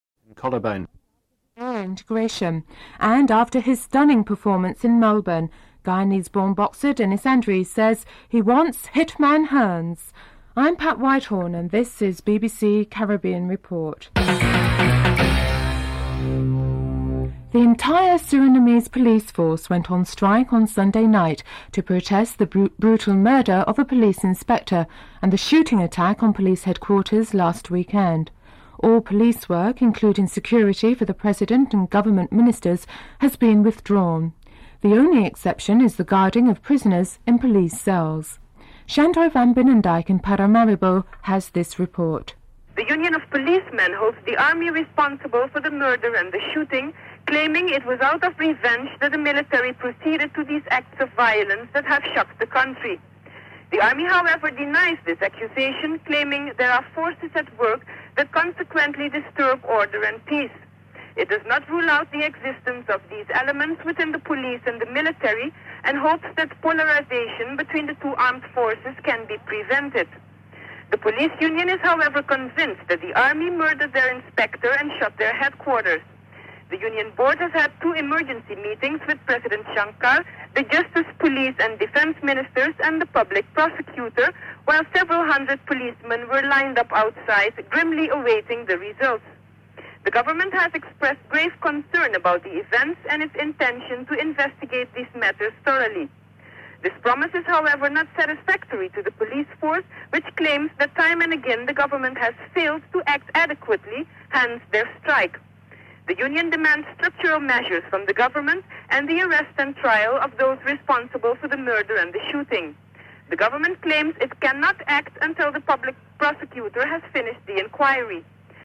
Report commences at the end the headlines segment.